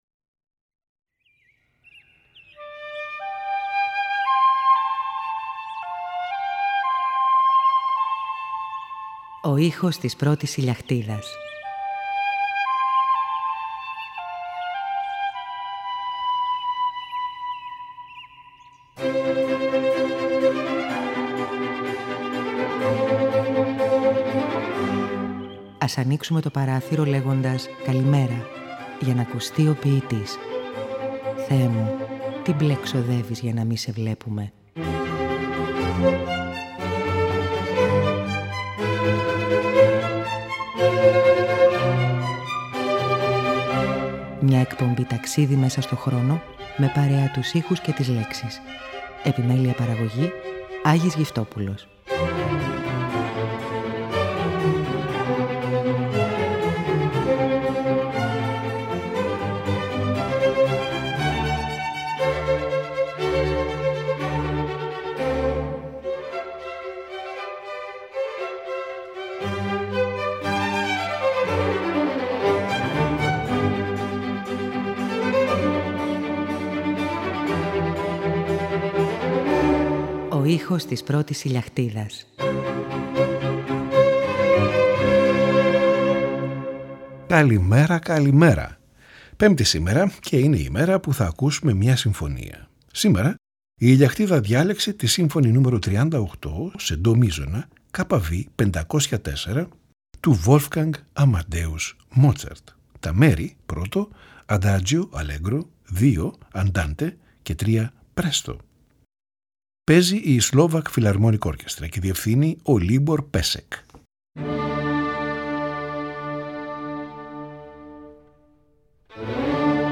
SYMPHONY NO 38
PIANO CONCERTO IN D Παραγωγή